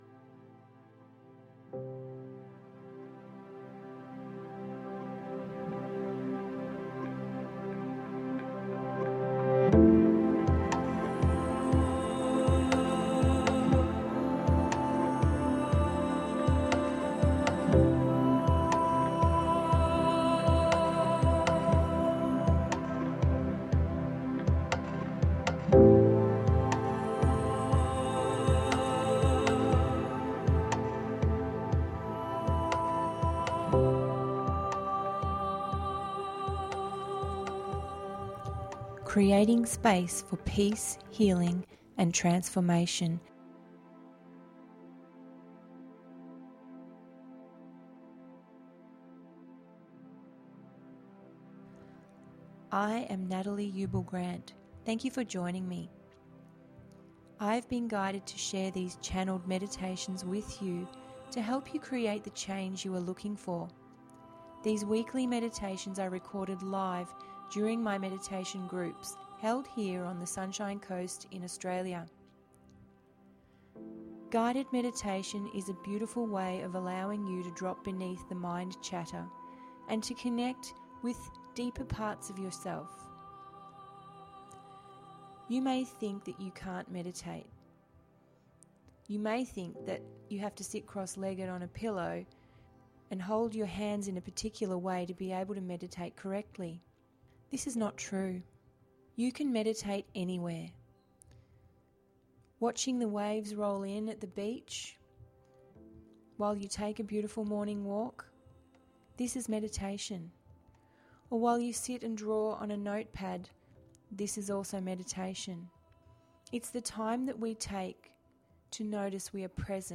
Guided Meditation duration approx. 22 mins